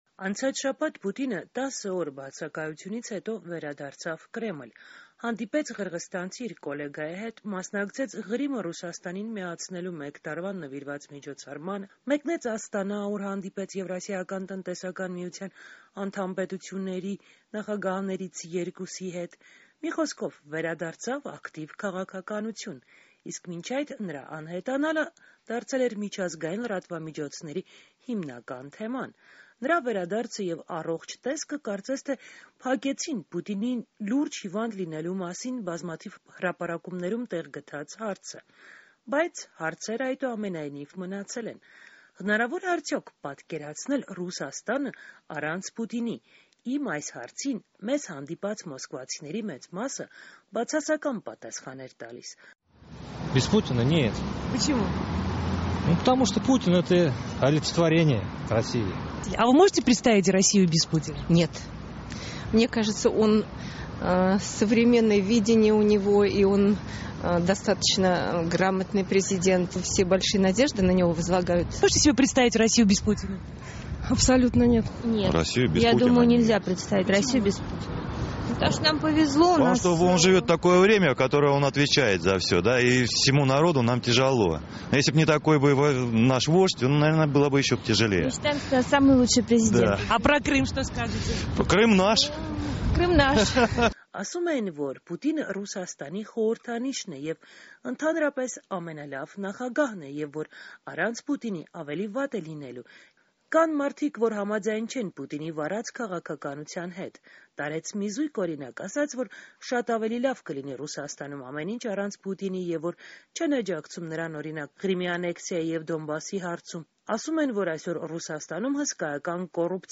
Ռուսաստանն առանց Պուտինի․ hարցում մոսկվացիների շրջանում